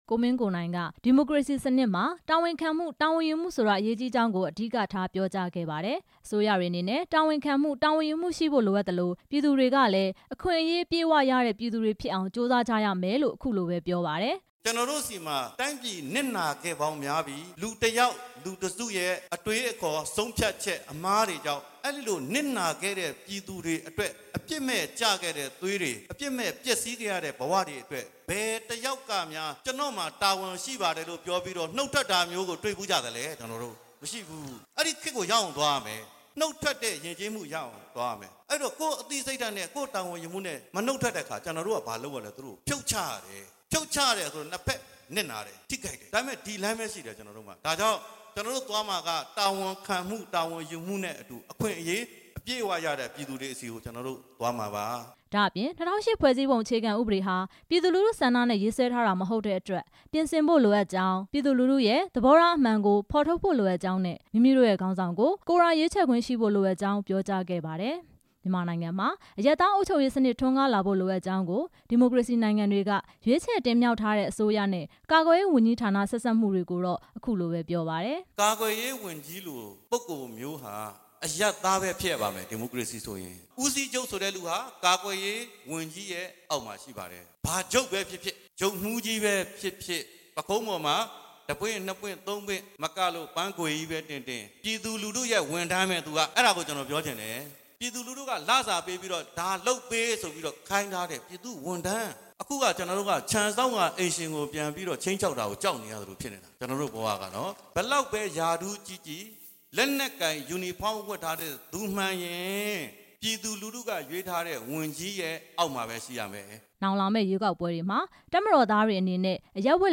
ပဲခူးတိုင်းဒေသကြီး ပြည်မြို့မှာ ဒီနေ့ ပုဒ်မ ၄၃၆ ပြင် ဆင်ရေး ဟောပြောပွဲမှာ ဒီမိုကရေစီစနစ်မှာ တာဝန်ယူမှု တာဝန်ခံမှုရှိဖို့ လိုအပ်တဲ့အကြောင်း ပြောစဉ်အခုလို ထည့်သွင်းပြောခဲ့ပါတယ်။